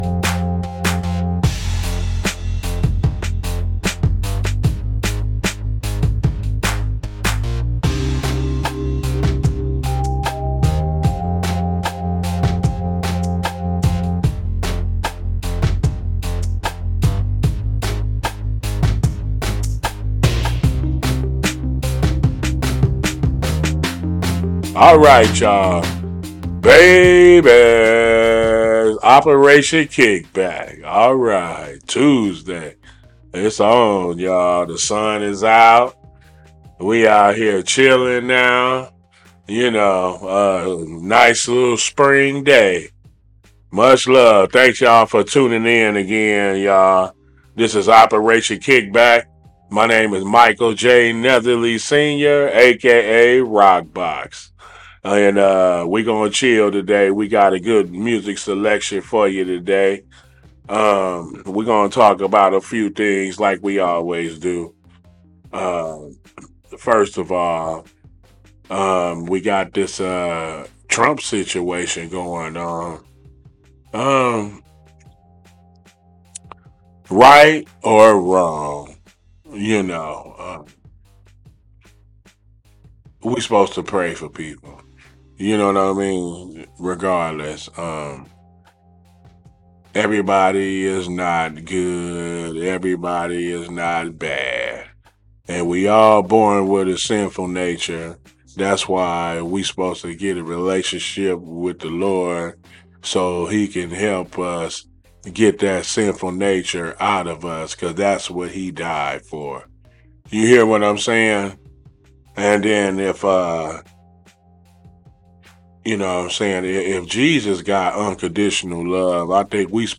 This episode of Operation KickBack aired live on CityHeART Radio at 1pm
RAW-BROADCAST-Operation-KickBack-5.14.24.mp3